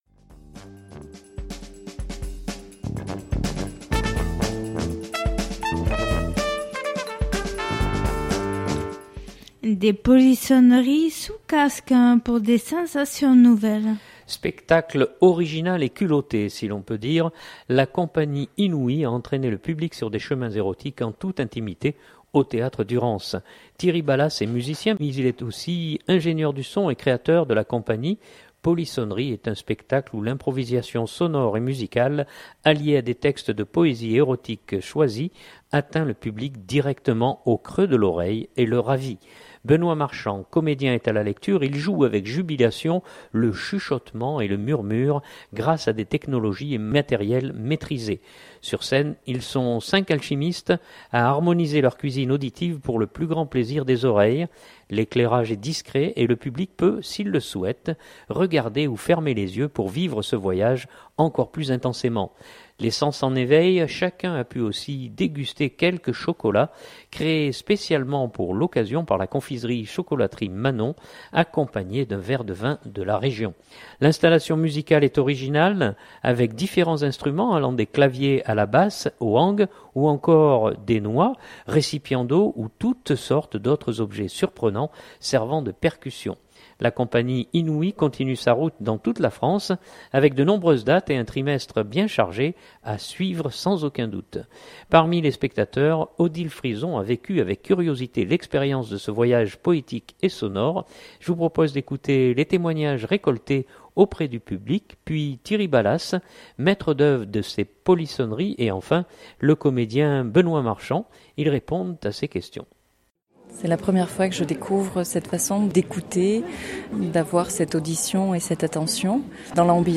Ils répondent à ses questions.